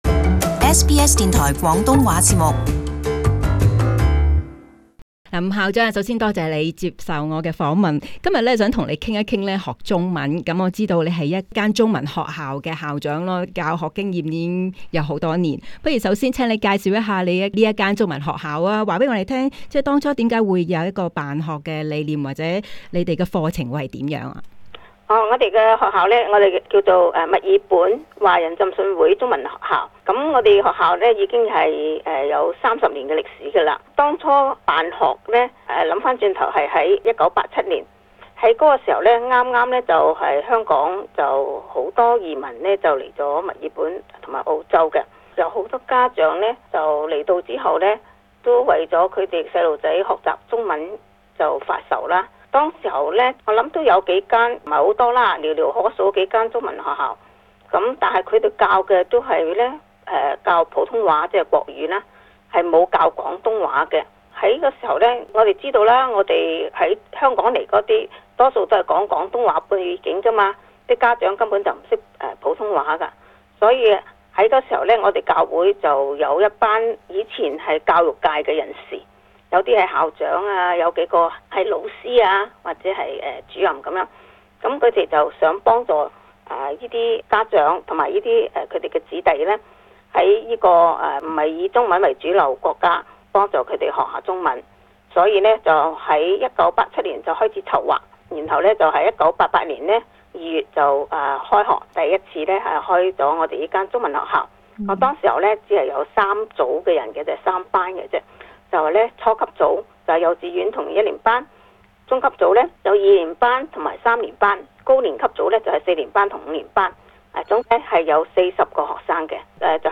【社區專訪】中文學校學粵語